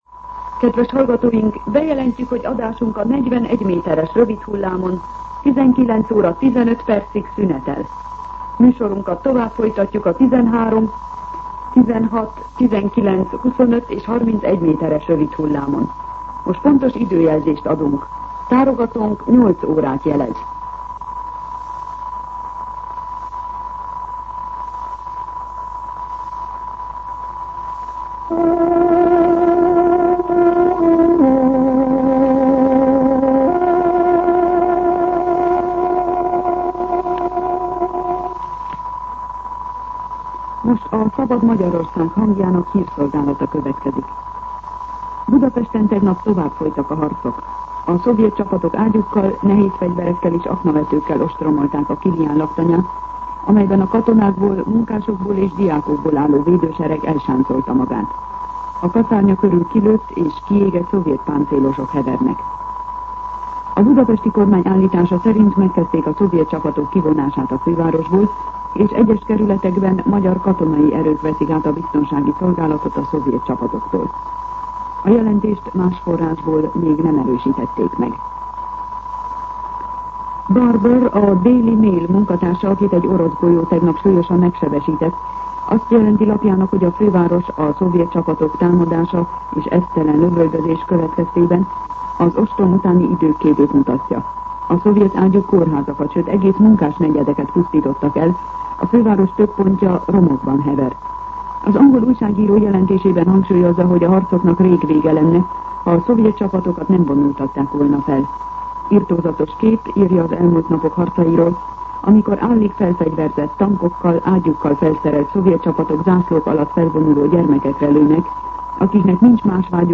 08:00 óra. Hírszolgálat